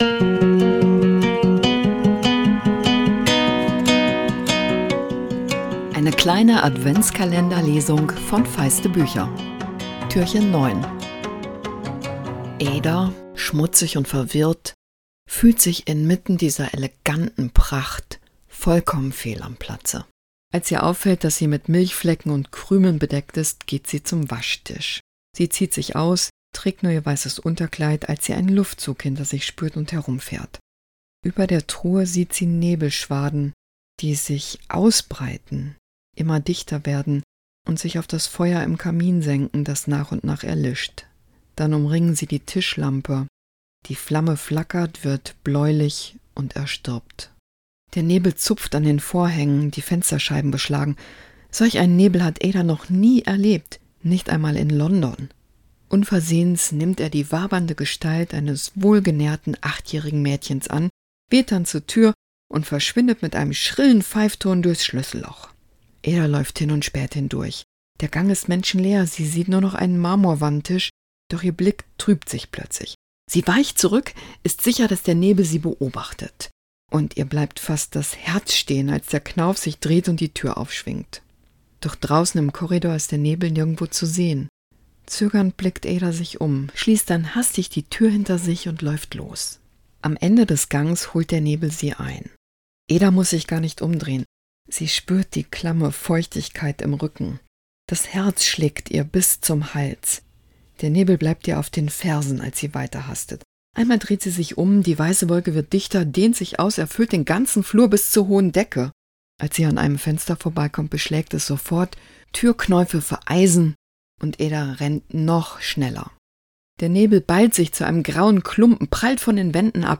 Adventskalender-Lesung 2024!